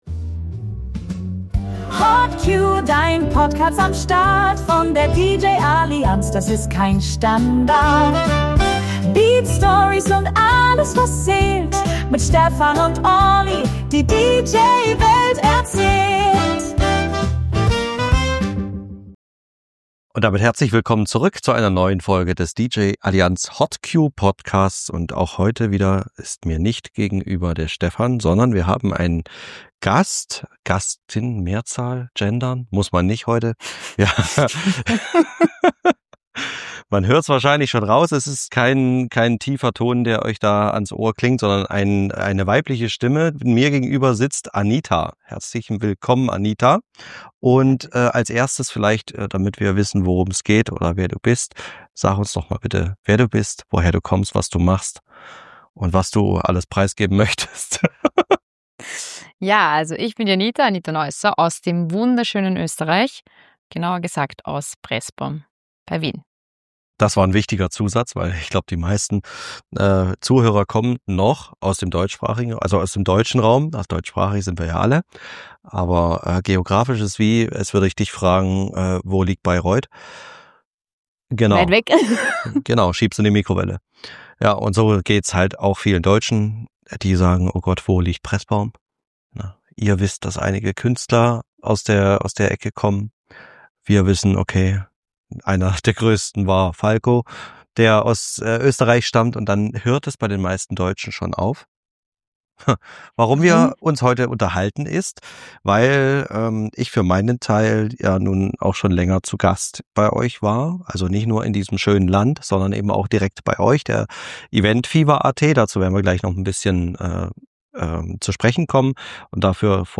Was sie erlebt hat, welche Klischees sie bis heute begleiten und warum sie trotzdem keinen anderen Weg einschlagen würde, erfahrt ihr in diesem spannenden Gespräch. Eine Folge über Mut, Selbstbestimmung und darüber, warum Kaffee kochen definitiv nicht zu ihren Aufgaben zählt.